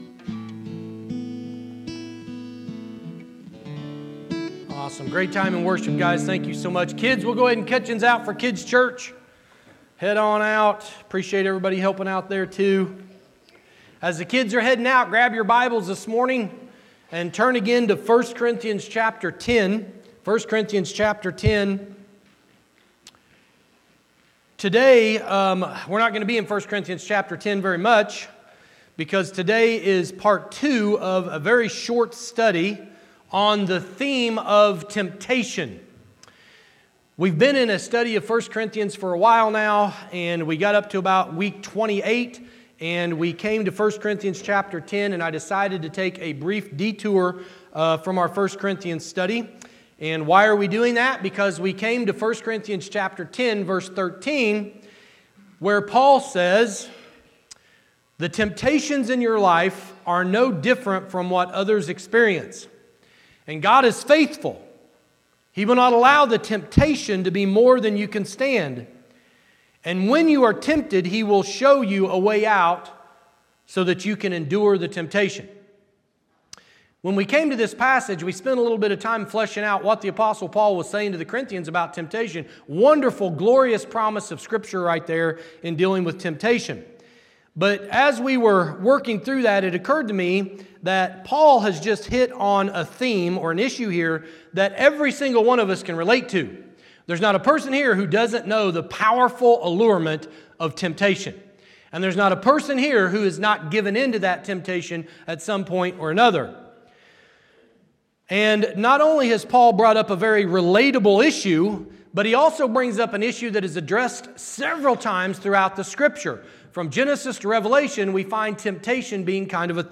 Church in Action Sermon Podcast